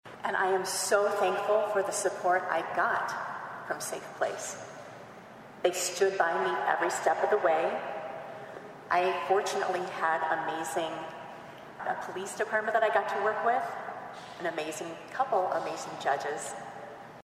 THIS IS NATIONAL CRIME VICTIMS’ RIGHTS WEEK AND WOODBURY COUNTY OFFICIALS HELD A GATHERING AT THE COURTHOUSE THURSDAY TO CALL ATTENTION TO LOCAL VICTIMS AND WHAT THEY HAVE BEEN THROUGH.